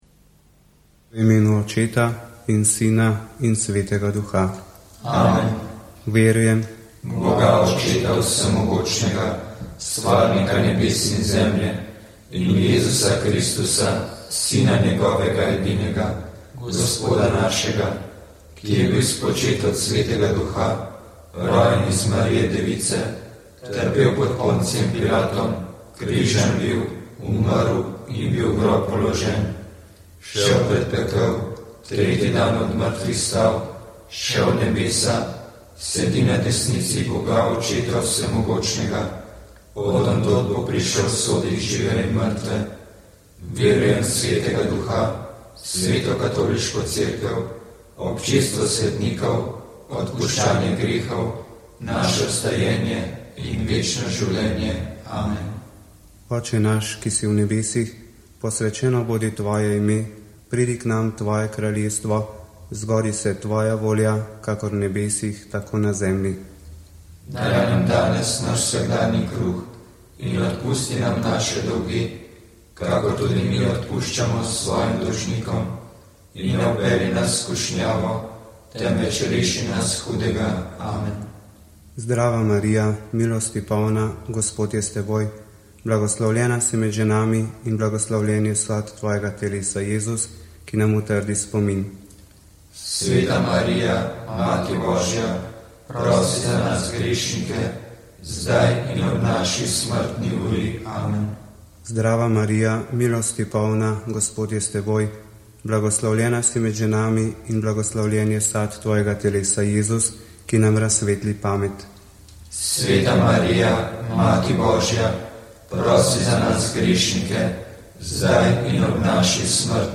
Sv. maša iz stolne cerkve sv. Janeza Krstnika v Mariboru 10. 9.